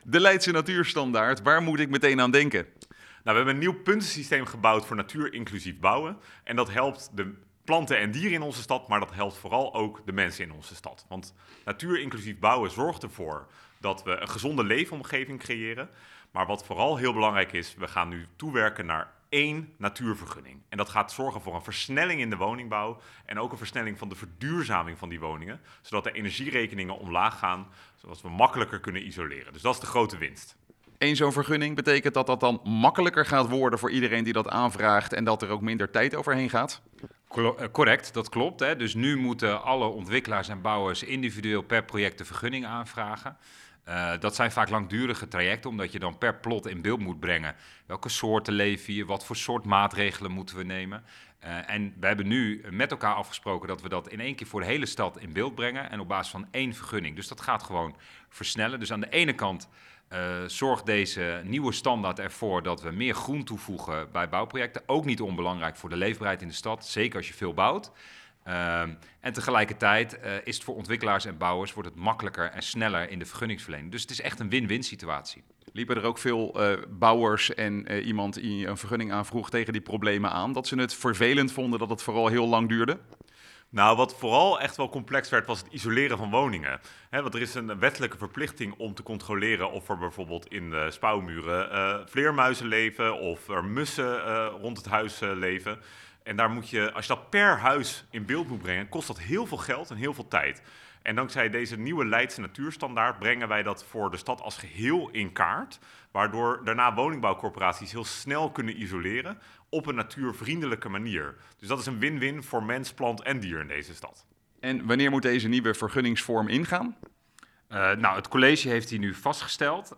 Wethouders Ashley North en Julius Terpstra over de Leidse Natuurstandaard: